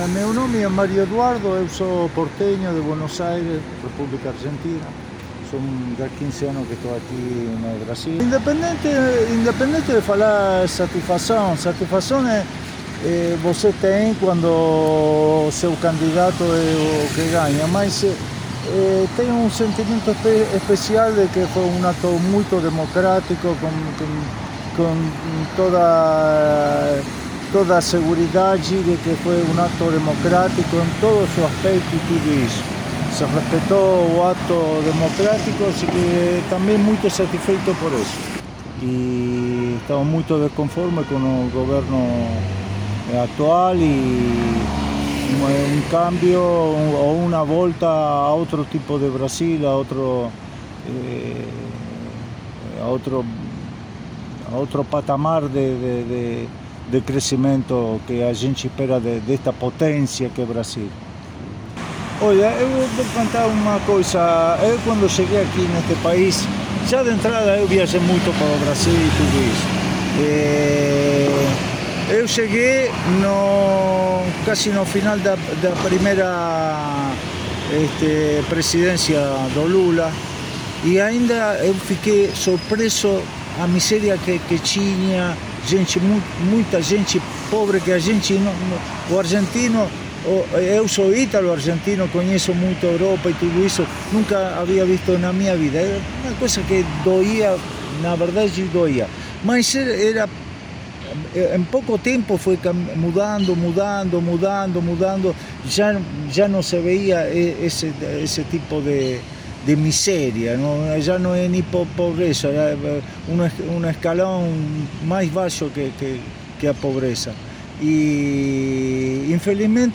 No início de nossa conversa, nos surpreendemos quando ouvimos um sotaque diferente.
Depoimento em áudio